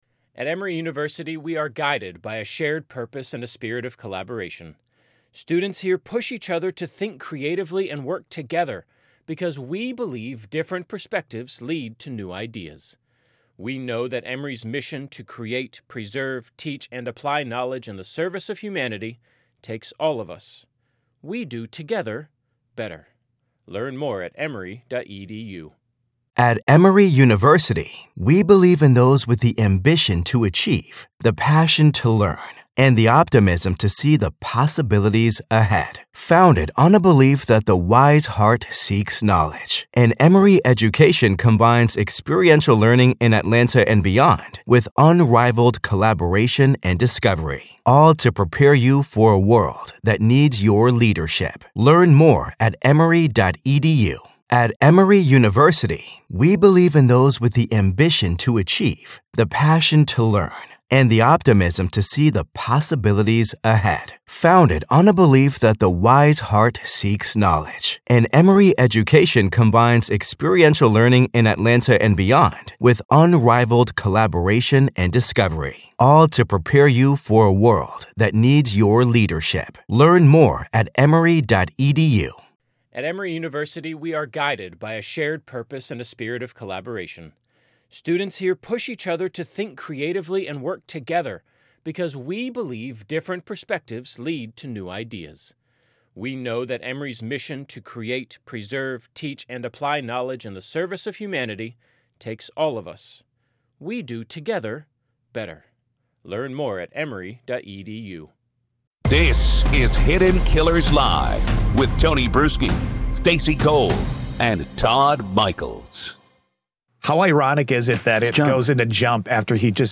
The hosts break down each claim, exposing how Daybell recasts traumatic events as supernatural confirmation of his “prophetic” status.
With humor, skepticism, and sharp analysis, the conversation pulls apart the contradictions.